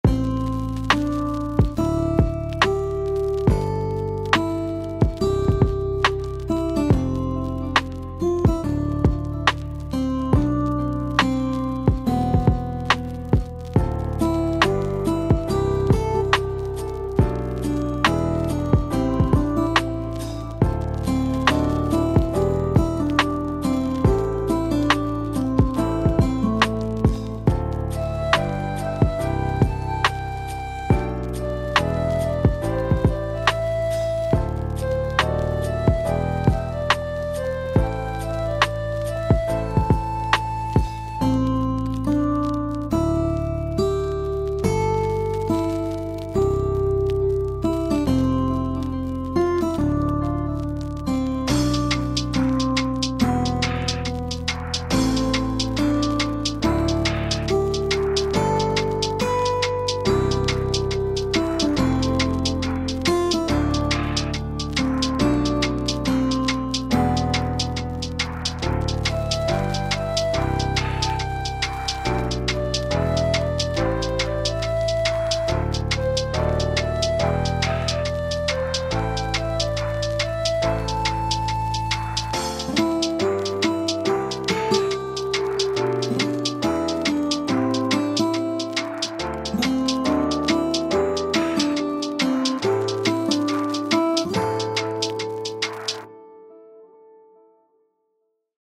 bonfireLOOP OGG